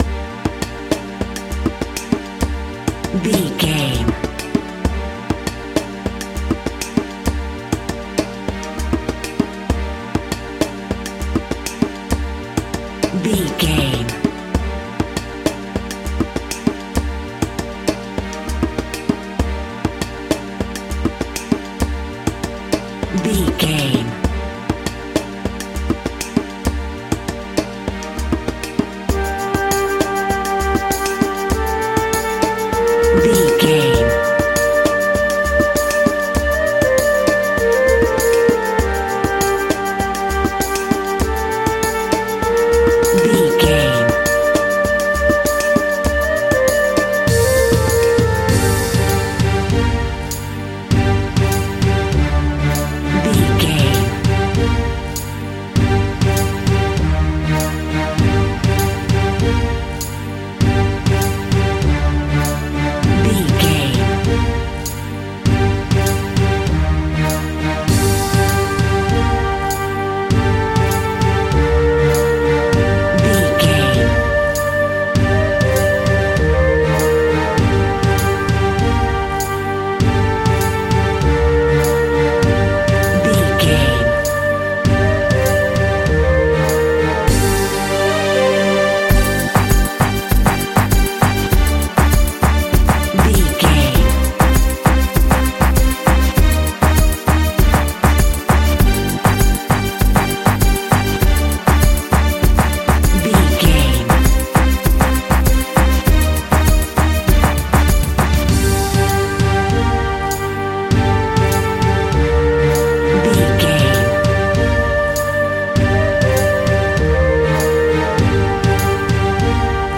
Aeolian/Minor
World Music
percussion
congas
bongos
kora
djembe
kalimba
udu
talking drum
marimba